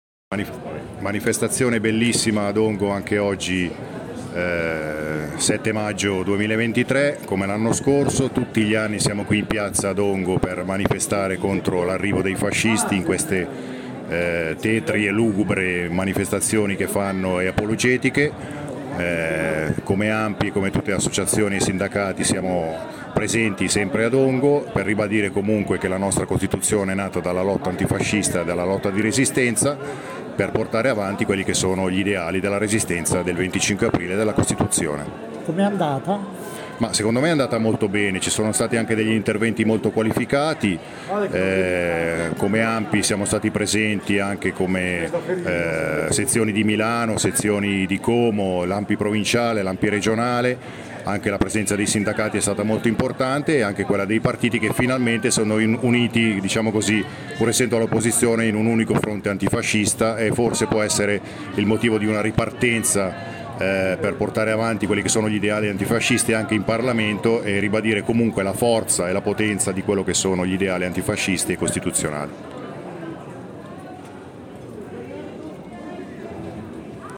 Dichiarazioni alla fine della manifestazione